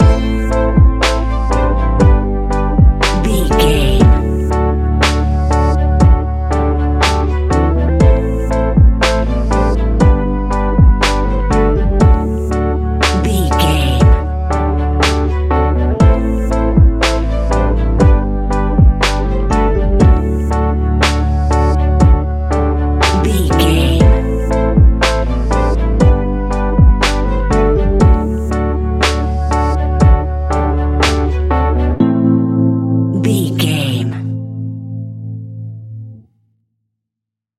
Ionian/Major
D♭
chilled
laid back
sparse
new age
chilled electronica
ambient
atmospheric